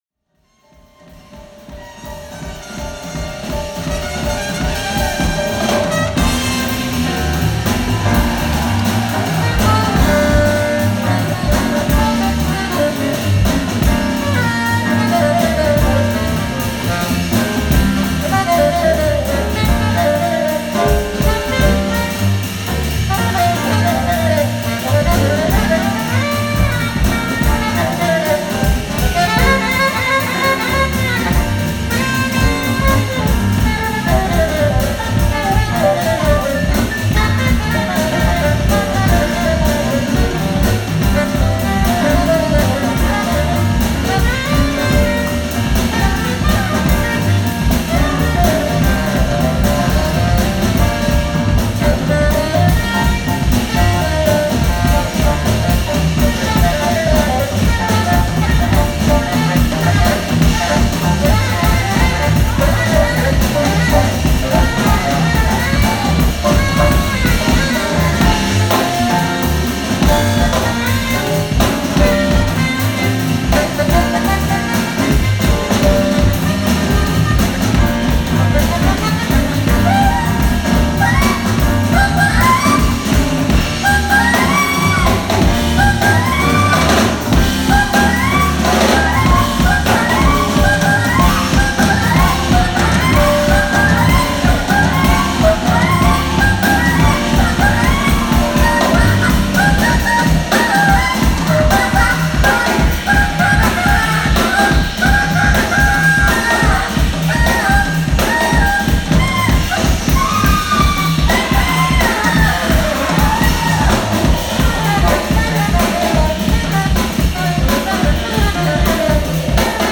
Solo Snippet Live at FUJI ROCK FESTIVAL '17